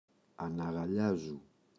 αναγαλλιάζω [anaγa’ʎazo]
αναγαλλιάζου.mp3